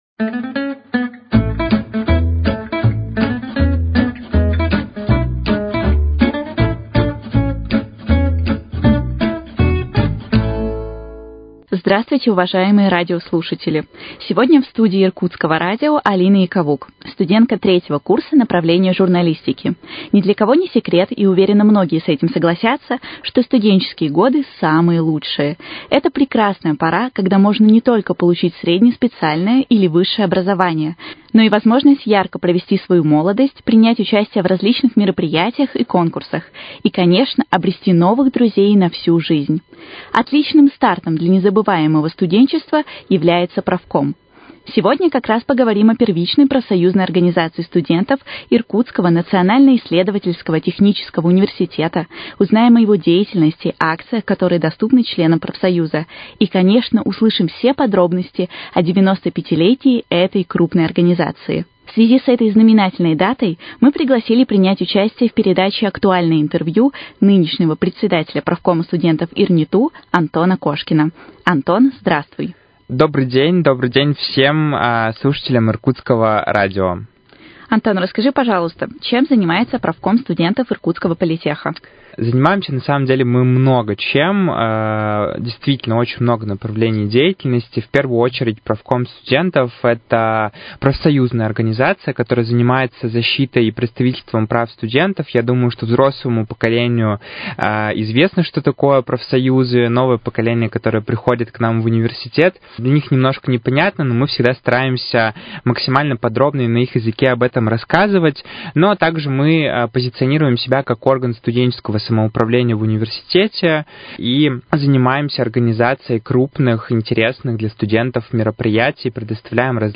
Актуальное интервью: 95-летие профкома ИРНИТУ